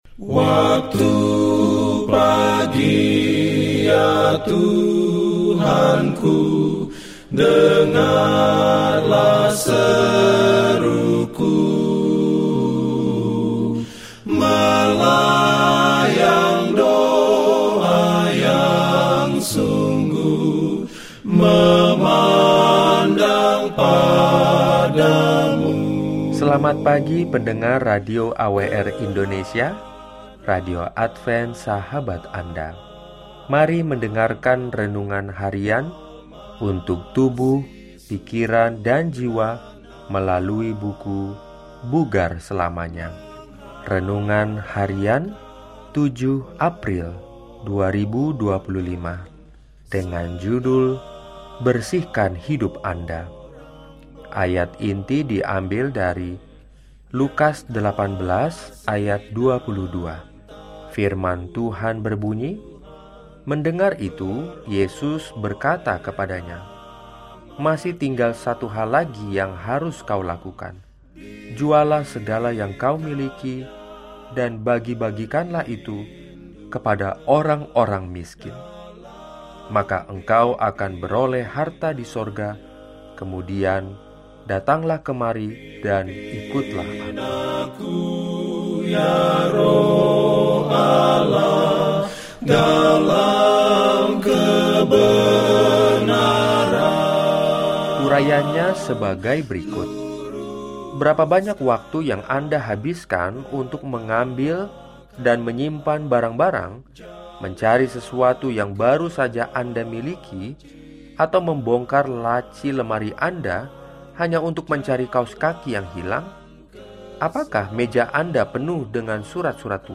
Daily Spiritual Devotional in Indonesian from Adventist World Radio